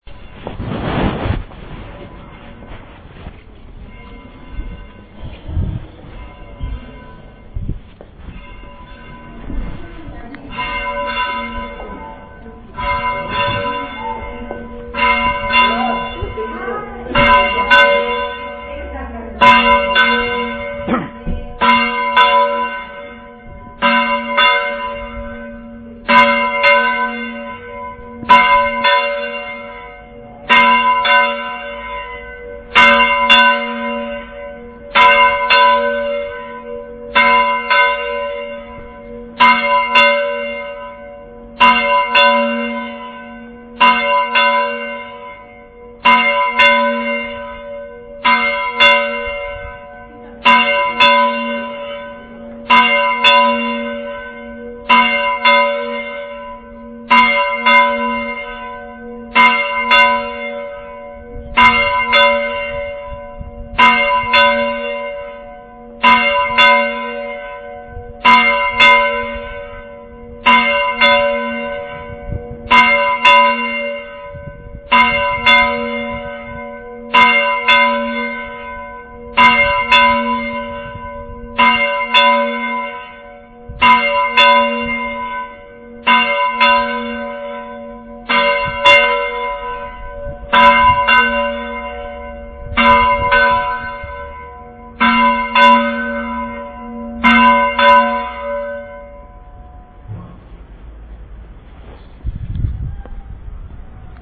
Campana de la Oliva
Campana-de-la-Oliva.mp3